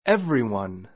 everyone 小 A1 évriwʌn エ ヴ リワン 代名詞 誰 だれ でも , みんな （everybody） ⦣ 単数扱 あつか い. ✓ POINT every を強調する時は every one と2語に書く.